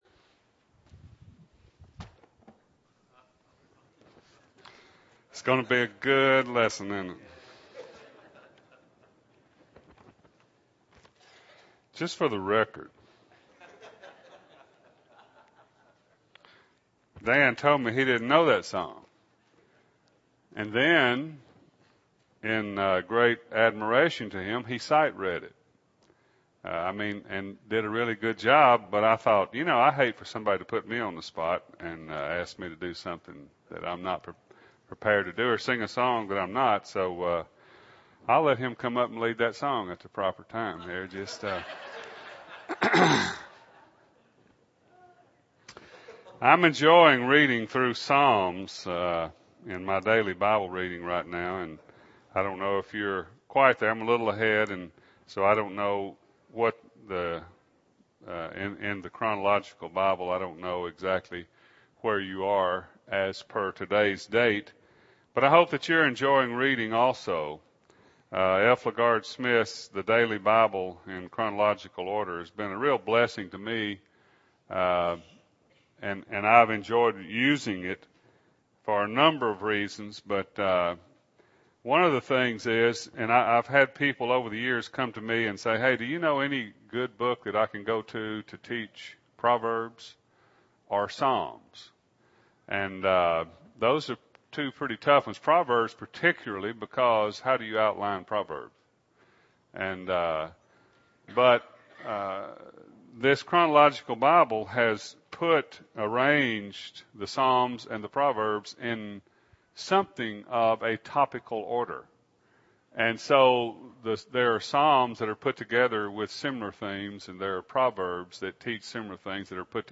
God’s Motivation – Bible Lesson Recording